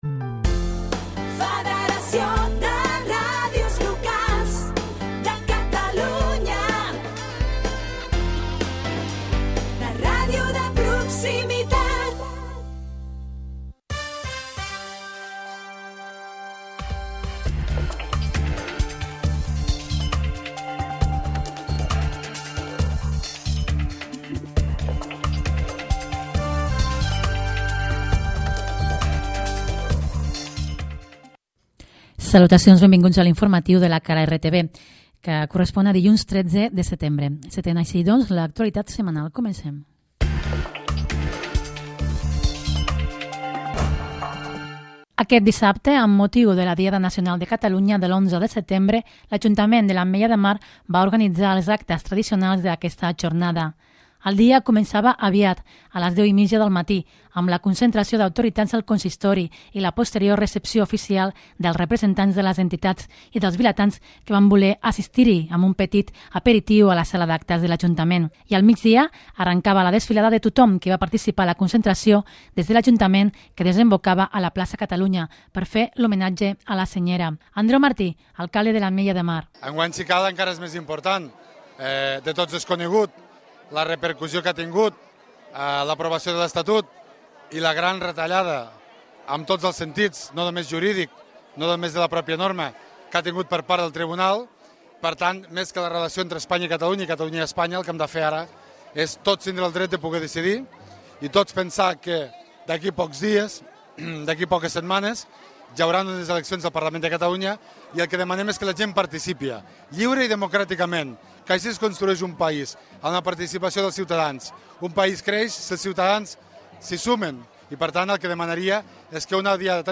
Butlletí informatiu